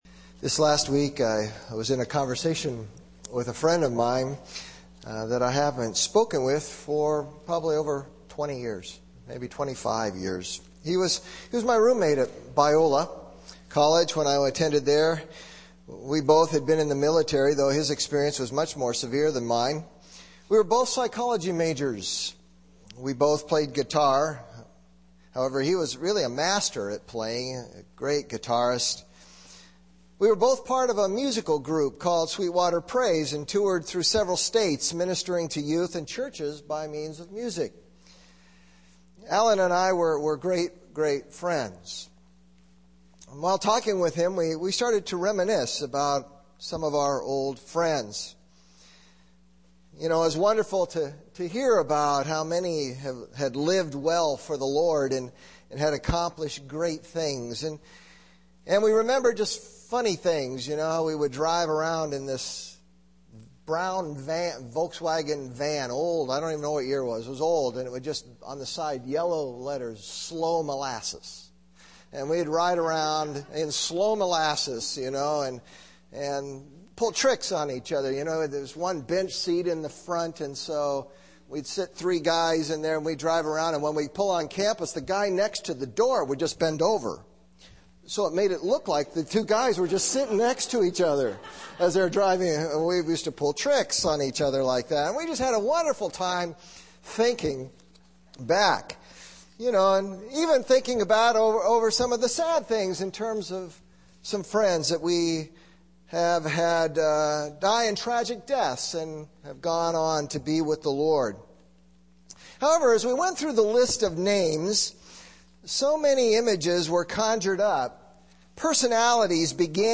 Primary Passage: Acts 3:11-18 Preacher